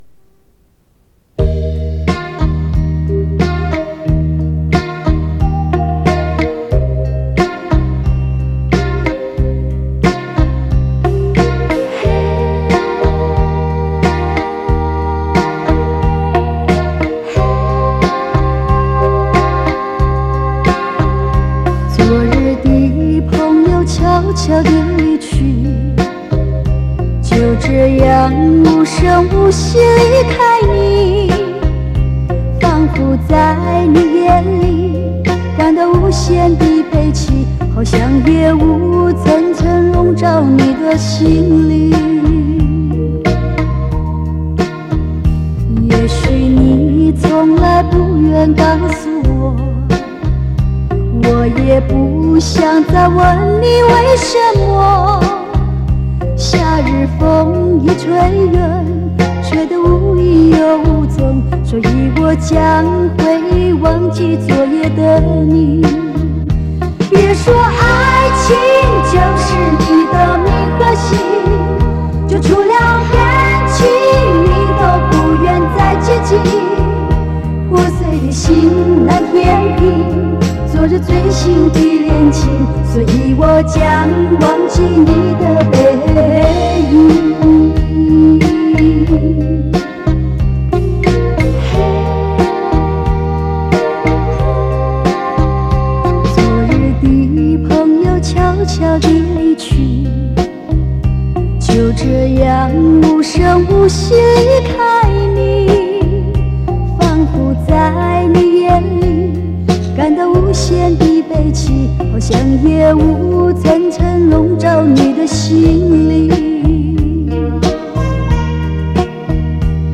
磁带数字化：2022-12-10
旋律动听，歌声醉人，美妙动听的歌声